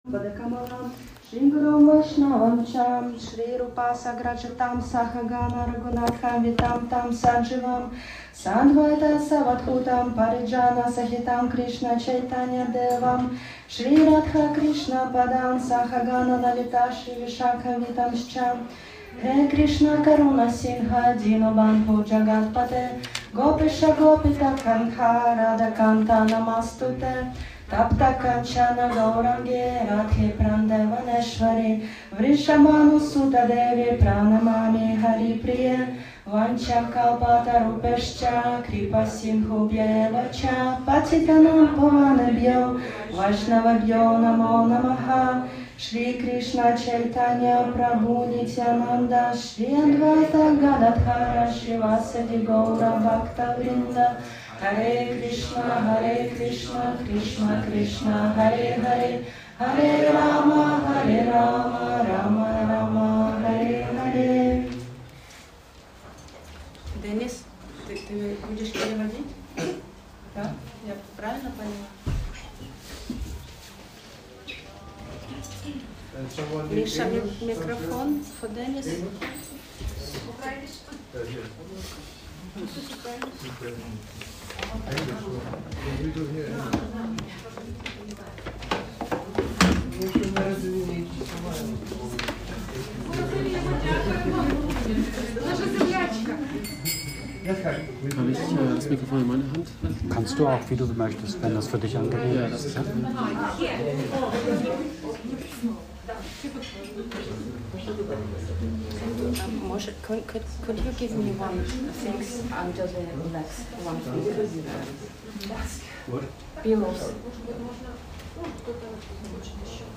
Die Geschichte um den Govardhan Hügel – Spiritueller Vortrag